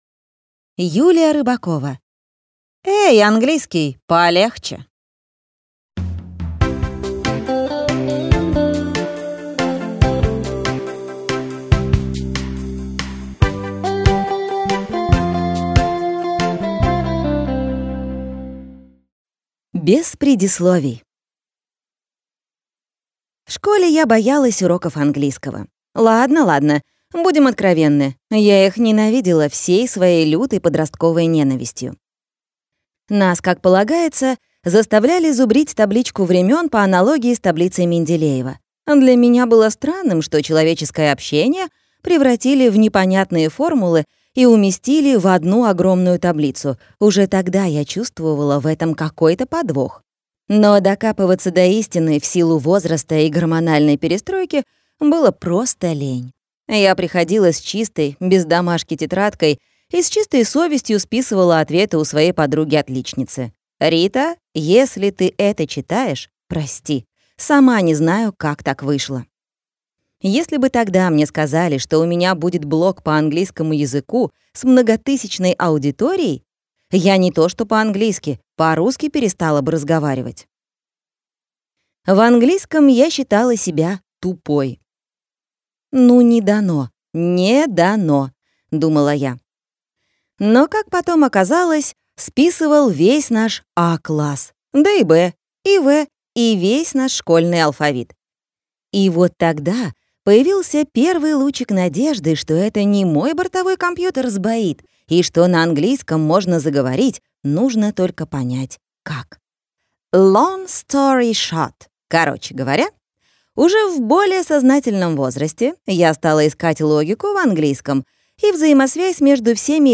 Аудиокнига Эй, английский, палехче!